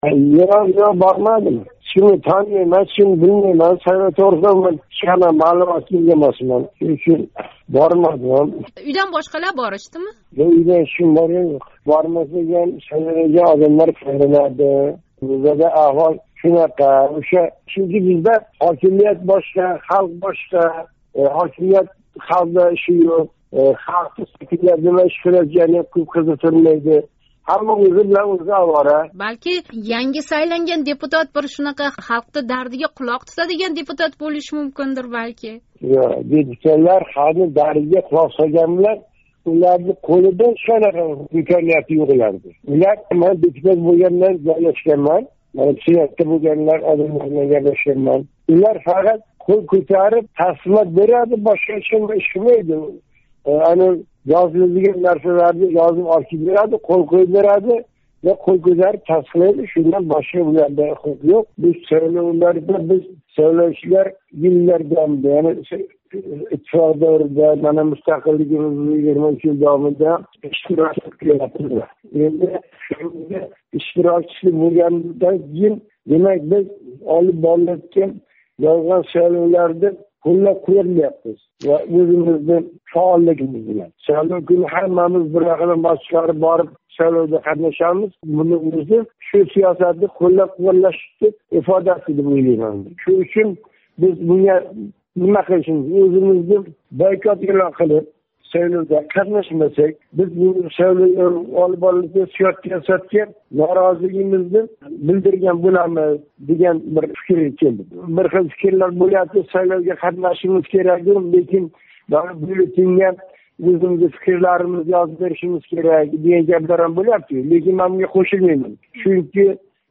Навоийлик тингловчи билан суҳбат